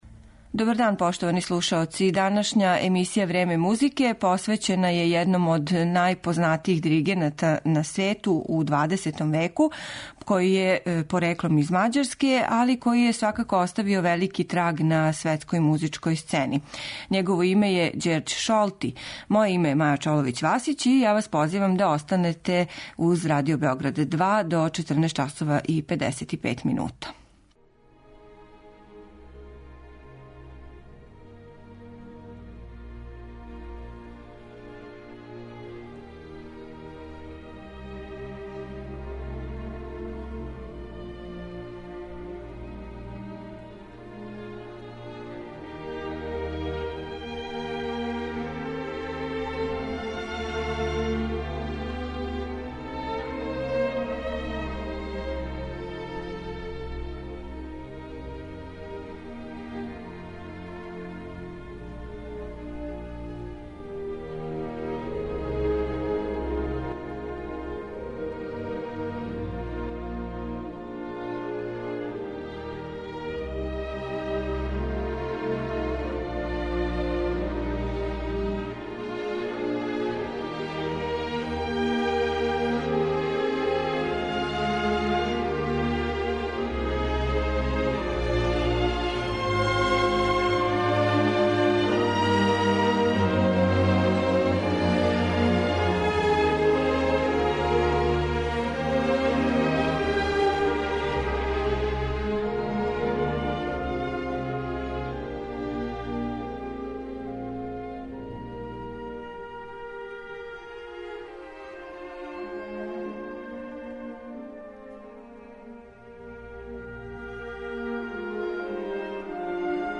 Његов музички портрет насликаћемо делима Чајковског, Малера, Елгара, Вагнера и Бартока.